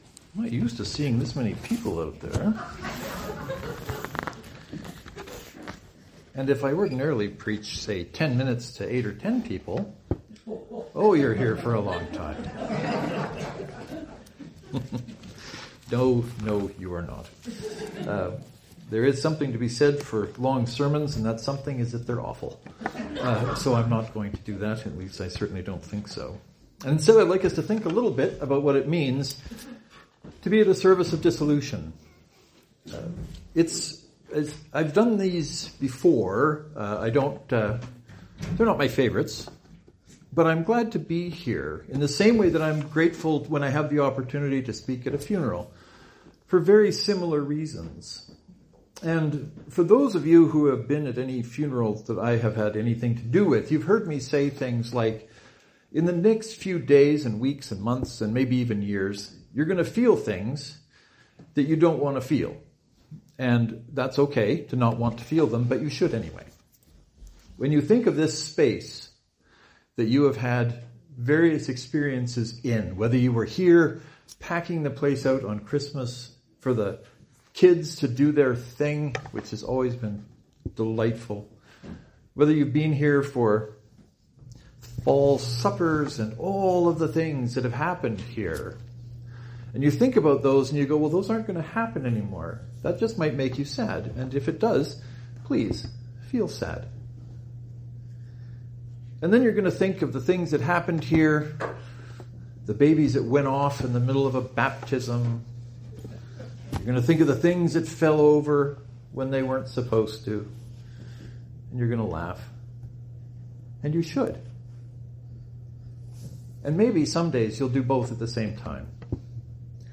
Last night, October 13, 2023, was the Service of Dissolution for Knox Presbyterian in Briercrest, SK.
I was of two minds about posting this sermon but in the end, I determined it may be helpful for some to be able to hear it or hear it again.